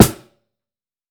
TC SNARE 13.wav